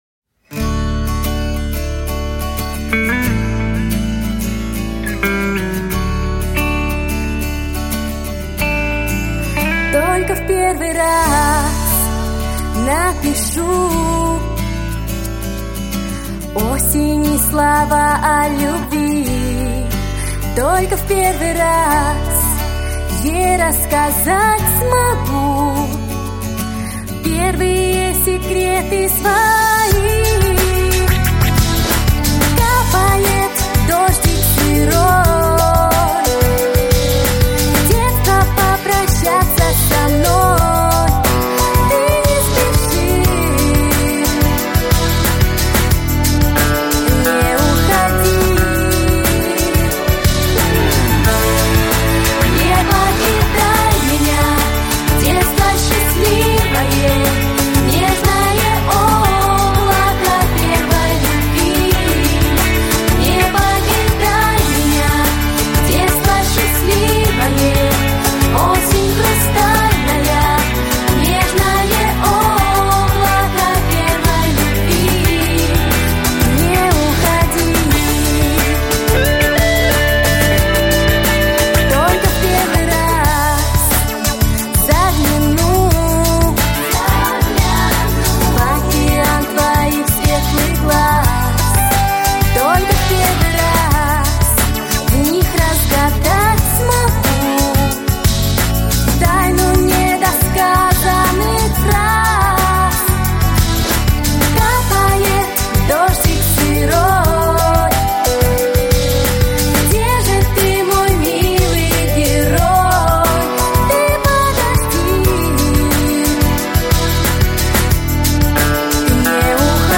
• Песня: Плюс, минус
• Категория: Детские песни